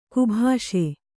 ♪ kubhāṣe